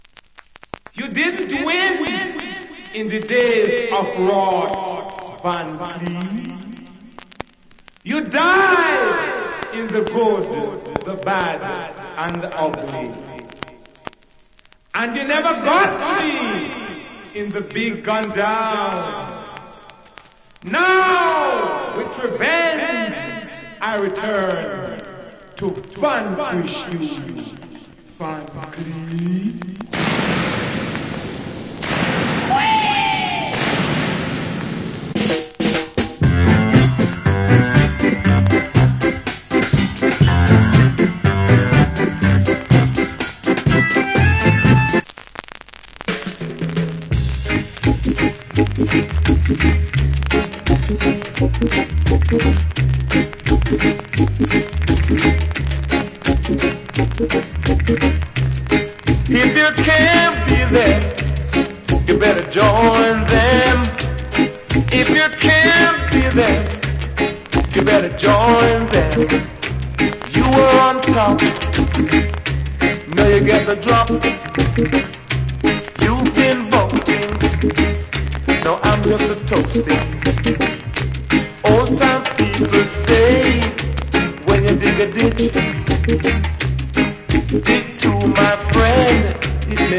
Killer Organ Early Reggae Inst.